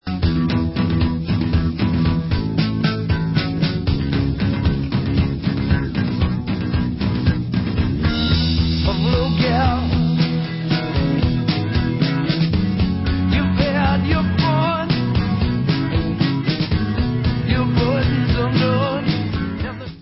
(live)
sledovat novinky v kategorii Rock